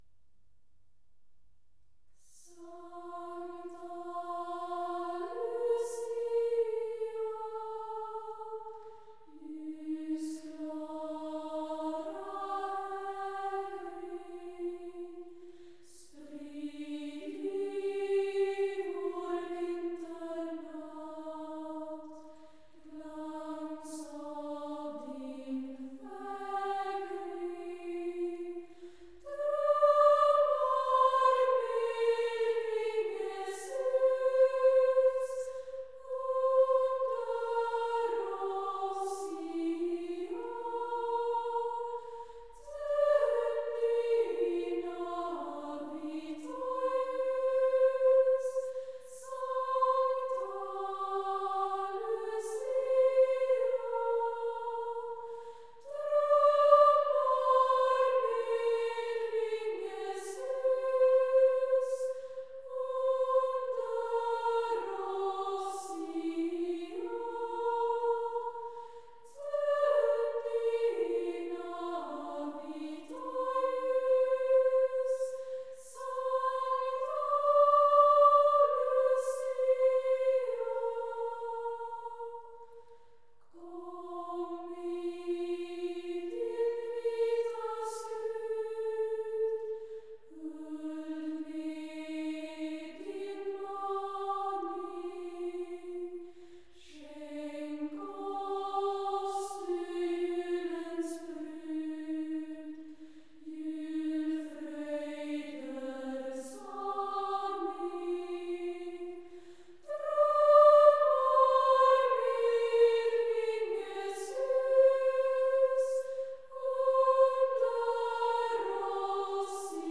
Swedish Christmas music